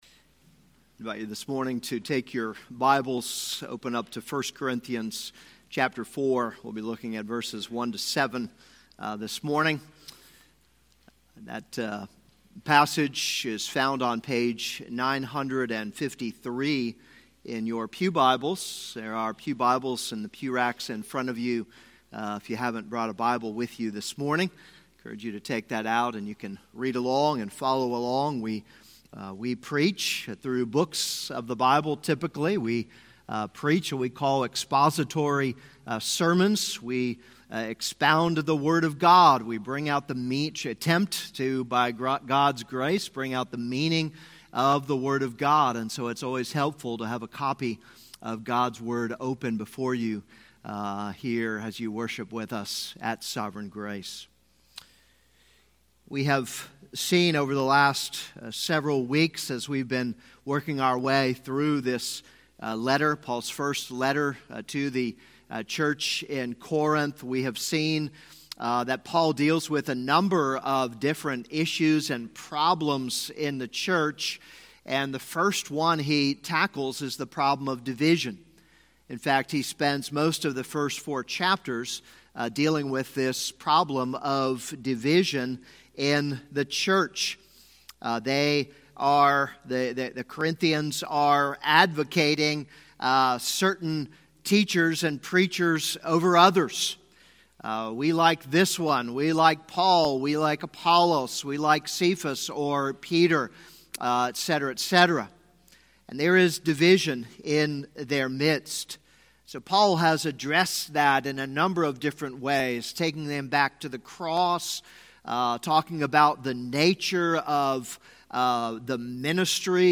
This is a sermon on 1 Corinthians 4:1-7.